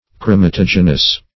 Search Result for " chromatogenous" : The Collaborative International Dictionary of English v.0.48: Chromatogenous \Chro`ma*tog"e*nous\, a. [Gr.